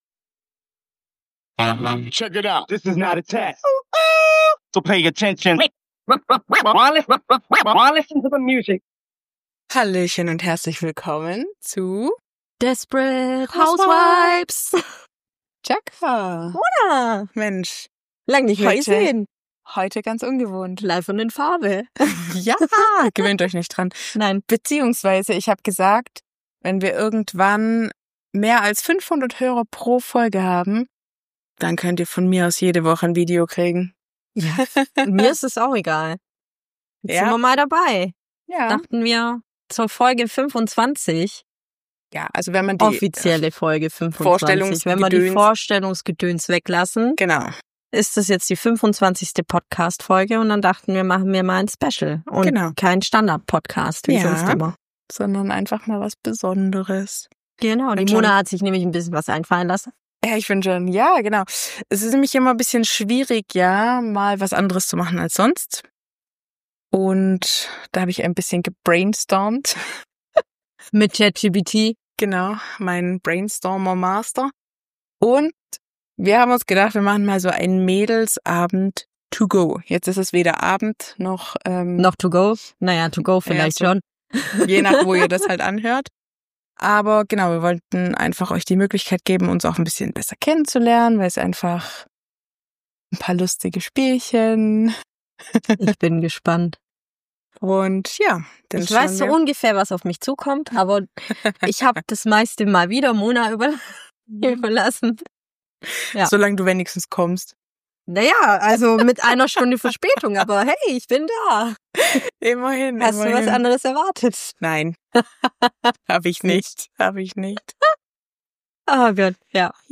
Beschreibung vor 5 Monaten Heute wird’s laut, ehrlich – und zum allerersten Mal auch mit Video! Wir bringen den Mädelsabend direkt in eure Ohren und auf eure Bildschirme: Zwischen „Ich hab noch nie…“, „Entweder oder“ und „Truth or Wine“ wird gelacht, gelästert und vielleicht ein bisschen zu viel verraten.